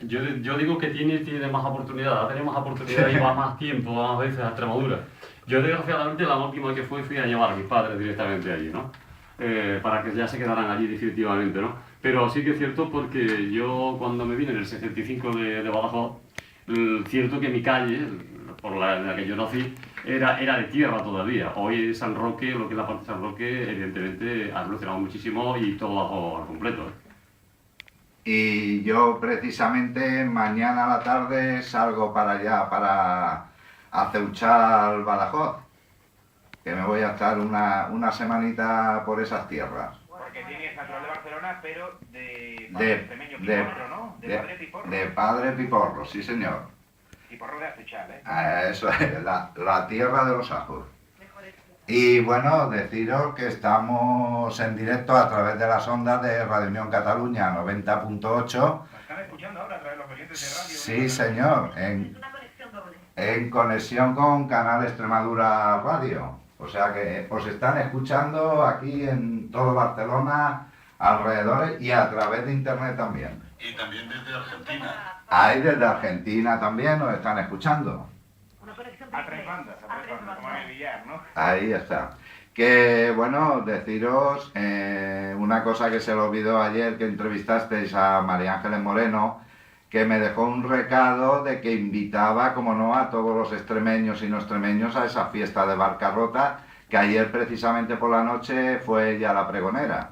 Dia d'Extremadura. Connexió amb Canal Extremadura Radio.
Entreteniment